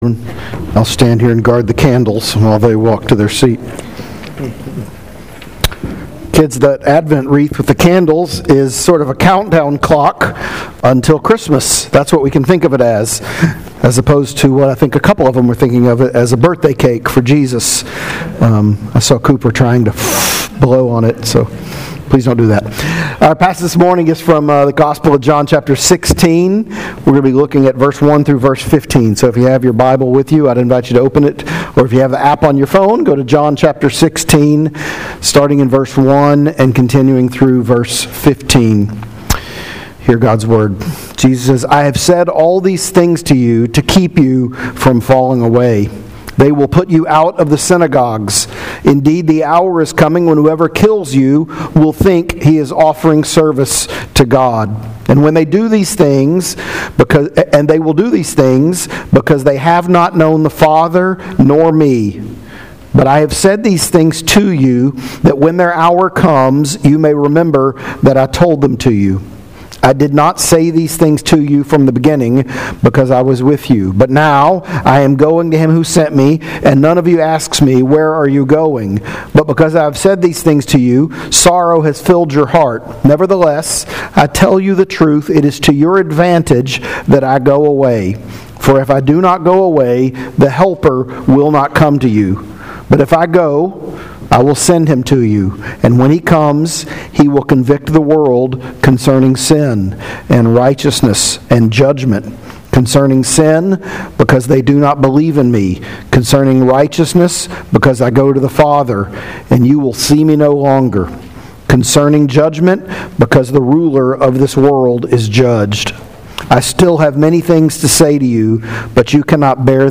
Sermons | Hope Church PCA